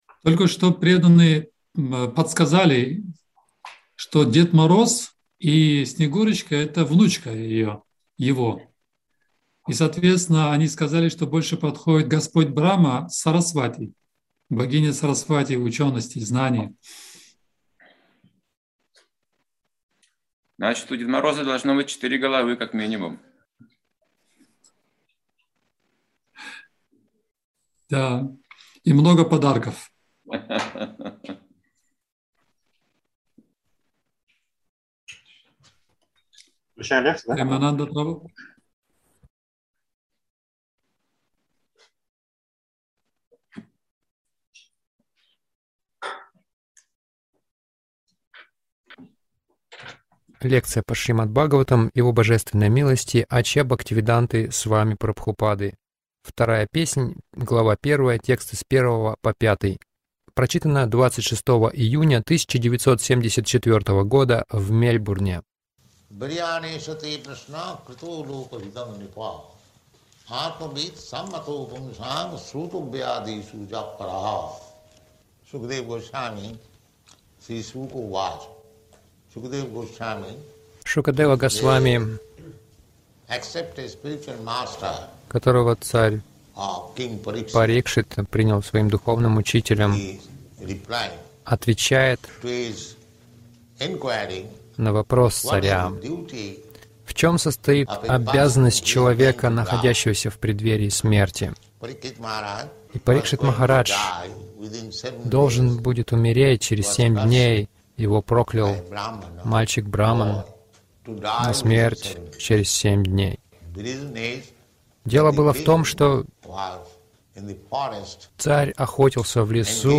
Лекция Шрилы Прабхупады
Вопросы и ответы